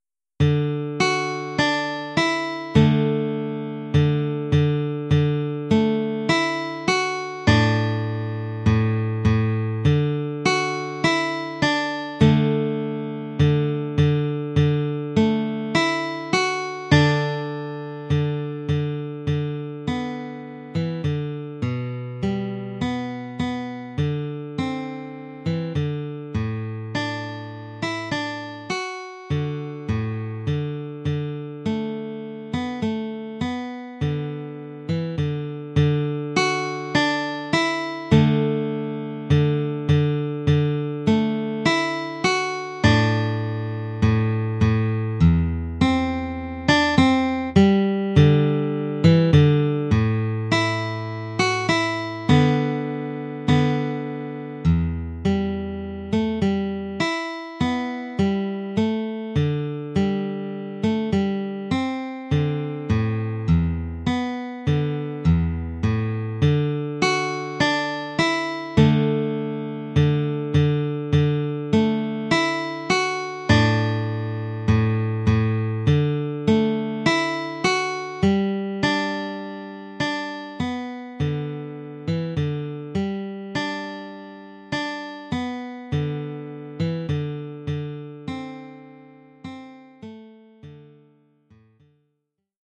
1 titre, guitare : partie de guitare
Oeuvre pour guitare solo.